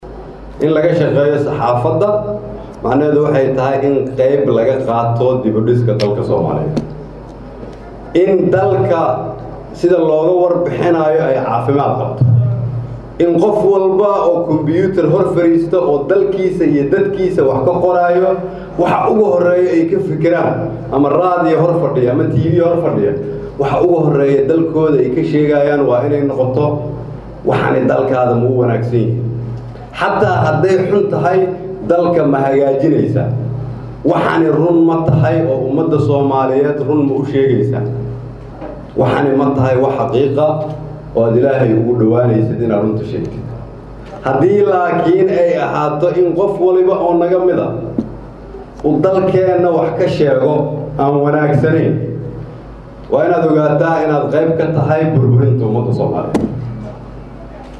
Hadalkaan ayaa Ra’iisul Wasaare kheyre waxa uu ka sheegay Shalay Munaasabad lagu daahfurayay kuliyada Cilmiga Saxaafada Jaamacadda Ummada taas oo lagu qabtay Xarunta Wasaarada Warfaafinta Soomaaliya.